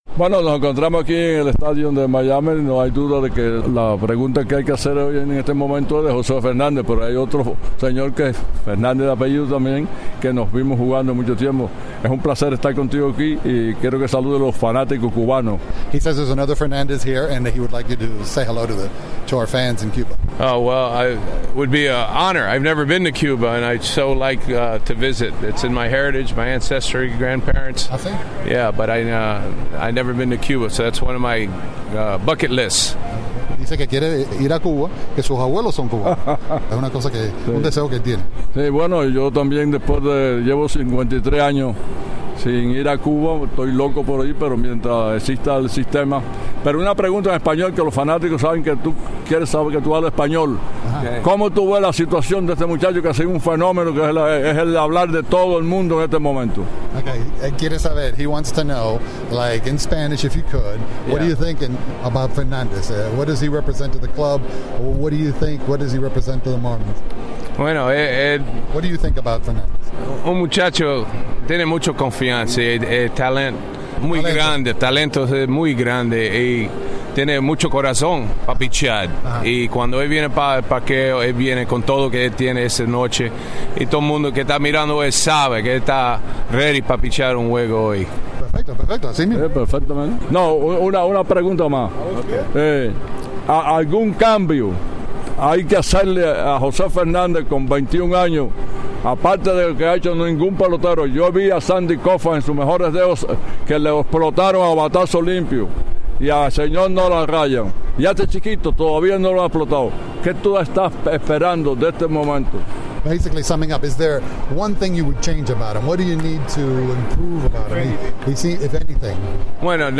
Entrevistas
en el parque de los Marlins